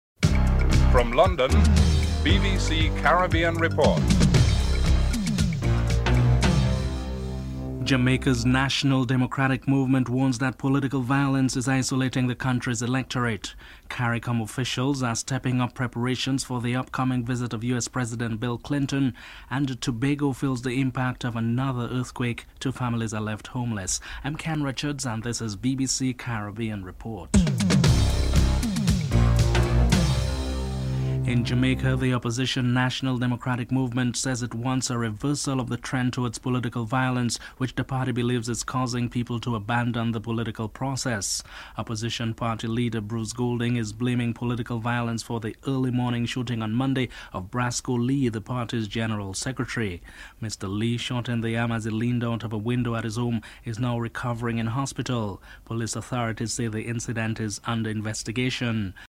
1. Headlines (00:00-00:30)
2. Jamaica's National Democratic Movement warns that political violence is isolating the country's electorate. Opposition party leader, Bruce Goldling is interviewed (00:31-04:06)